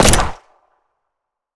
gun_pistol_shot_02.wav